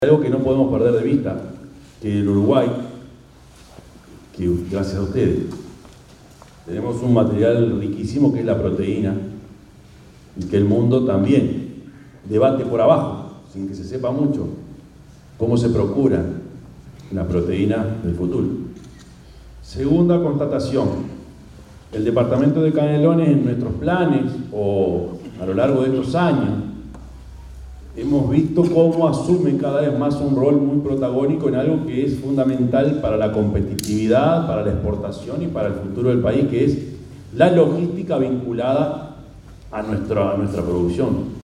El Intendente de Canelones, Yamandú Orsi, participó de la inauguración del nuevo centro de distribución y logística de semillas de Prolesa, ubicado en ruta 11 km 89.200, Santa Lucía, donde destacó la importancia del sector logístico para la producción canaria y el país.
Intendente de Canelones, Yamandú Orsi
yamandu_orsi_intendente_de_canelones_7.mp3